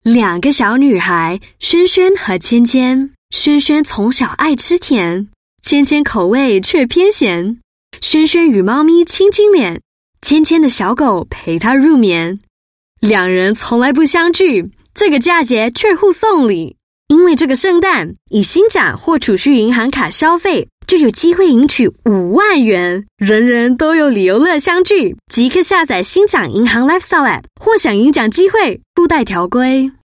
Voice Samples: Voice Demo 02
female